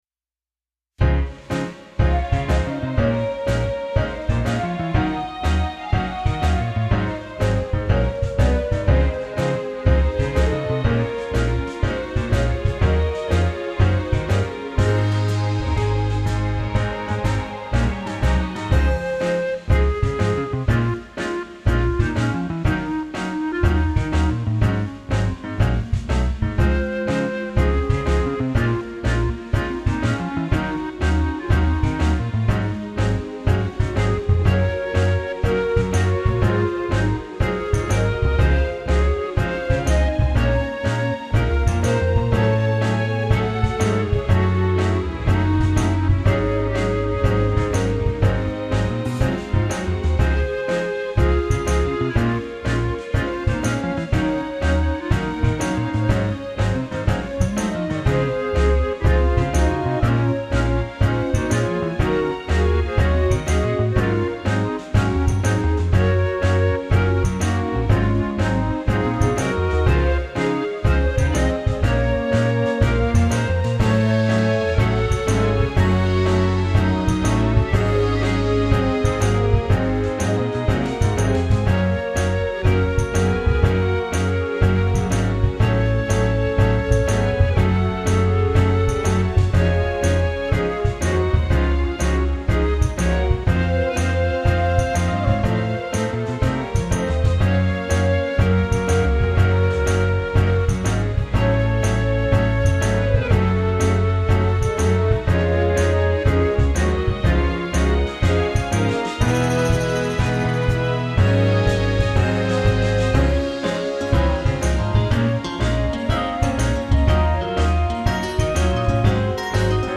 Sadness - Jazz, Band, Pop, Rock - Young Composers Music Forum
How's the volume on the bass?